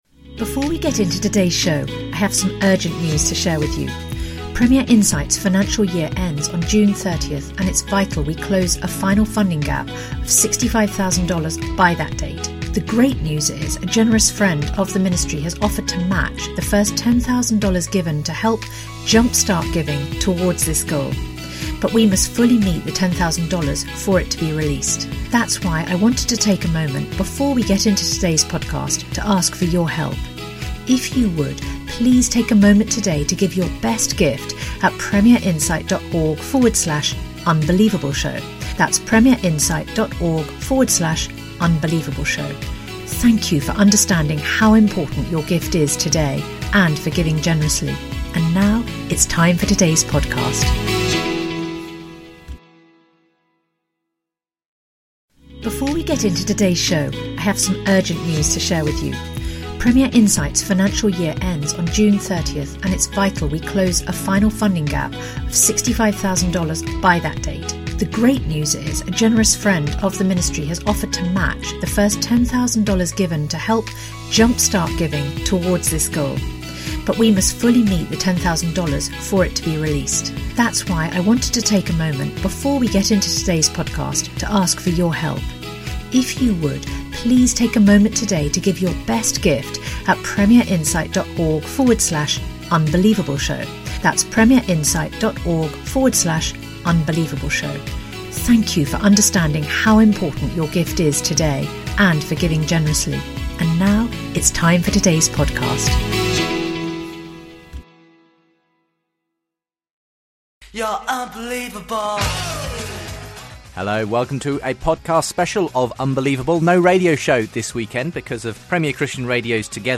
Christianity, Religion & Spirituality